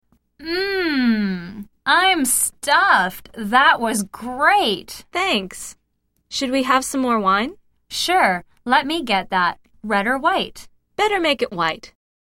來聽老美怎麼說